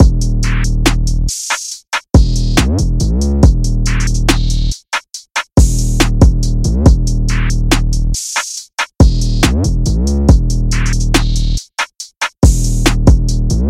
Tag: 130 bpm Trap Loops Drum Loops 2.31 MB wav Key : D FL Studio